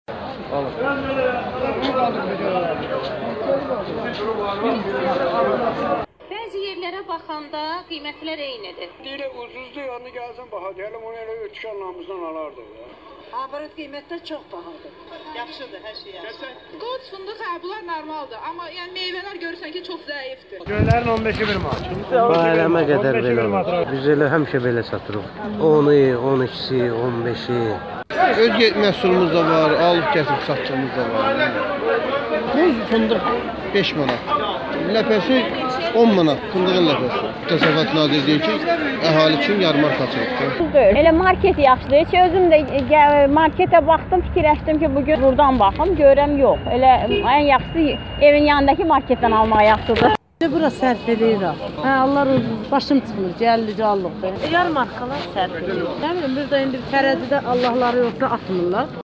Yarmarkadan səslər, alıcıların satıclların fikirləri